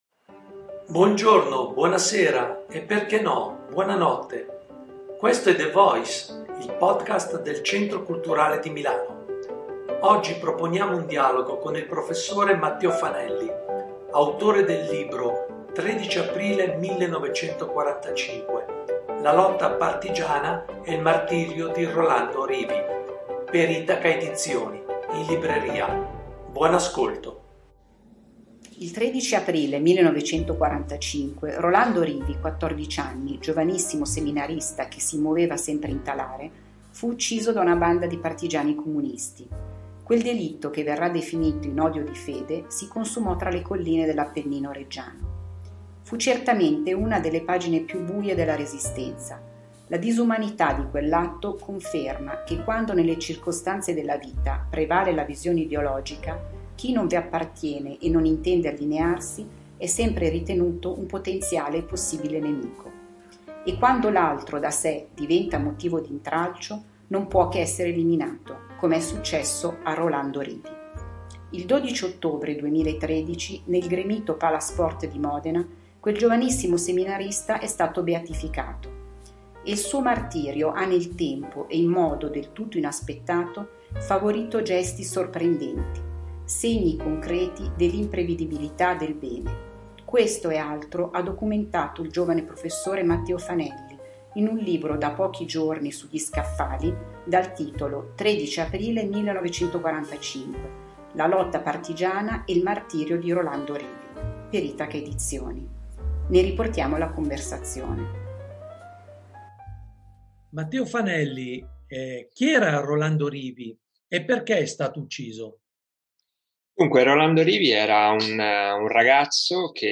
Il Podcast del CMC: dialogo